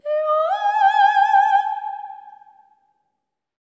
LEGATO 02 -R.wav